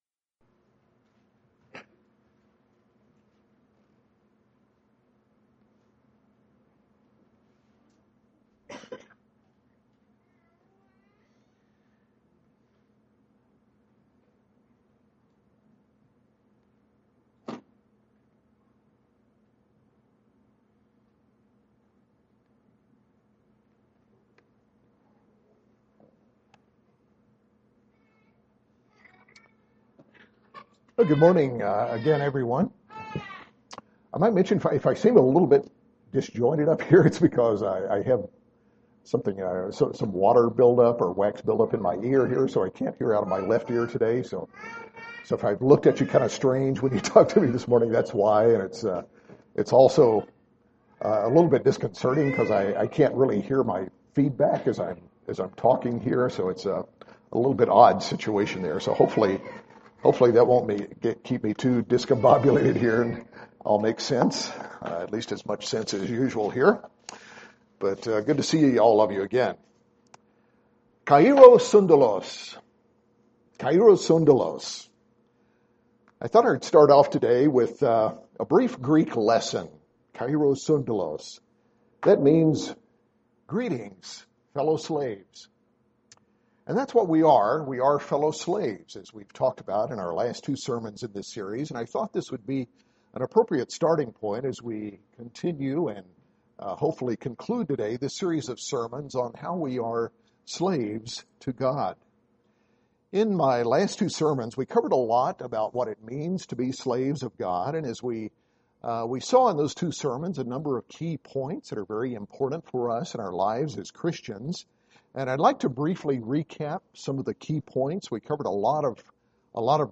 In this concluding sermon of a three-part series on what it means to be slaves to God, we examine five attributes of a faithful slave and how they apply to each of us today.